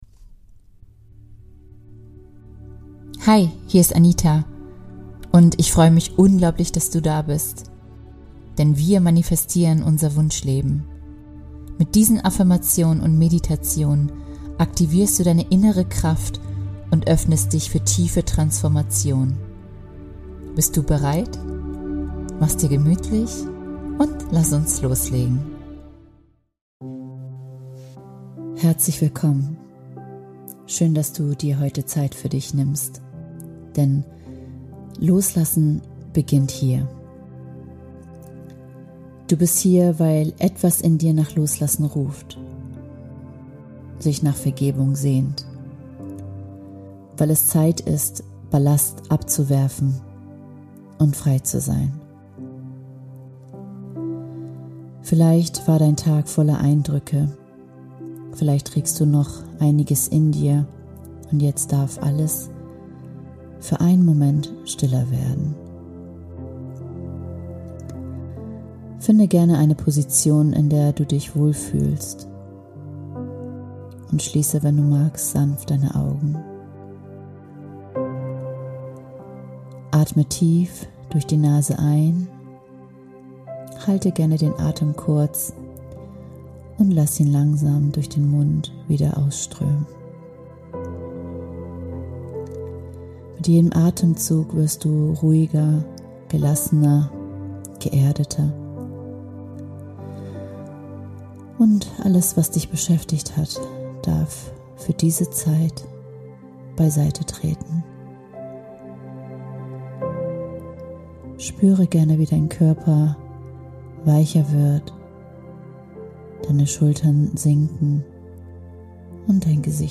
Entdecke in dieser Episode, wie du alte Verletzungen loslässt, inneren Frieden findest und dein Herz für Vergebung öffnest. Lass dich von Meditation, kraftvollen Affirmationen und sanften Visualisierungen begleiten – und nimm Heilung, Leichtigkeit und positive Energie mit in deinen Alltag.